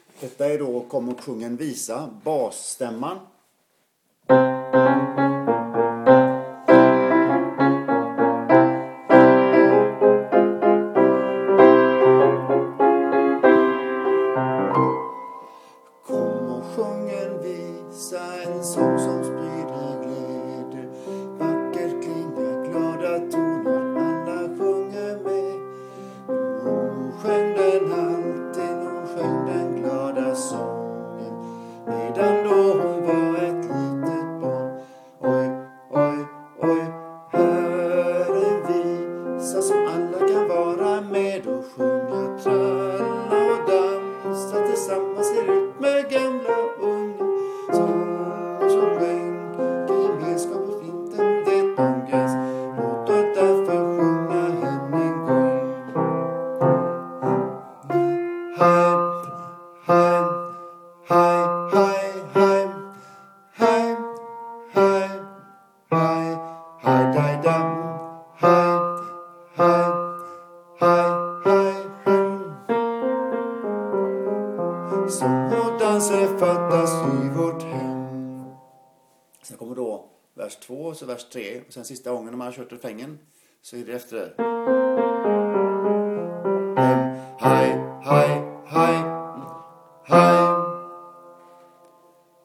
Kom och låt oss sjunga bas
Komochlatosssjunga_bas.mp3